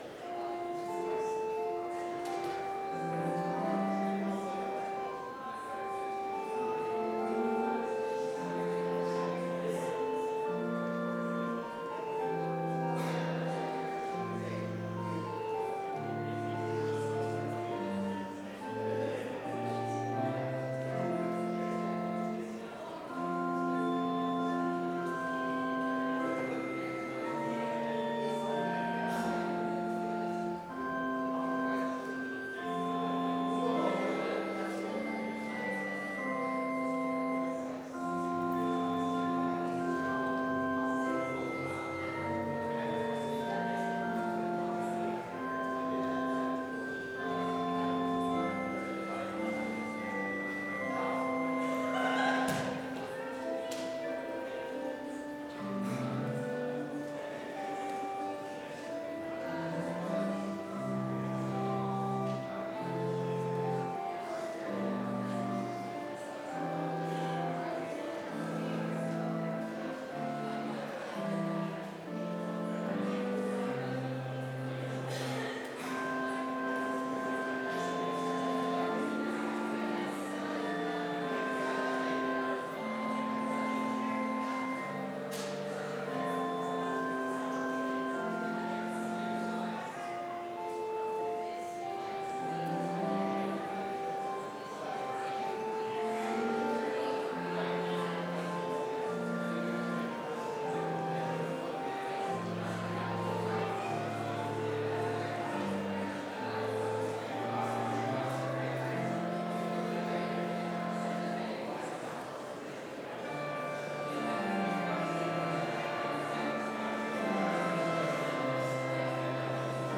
Complete service audio for Chapel - Monday, February 24, 2025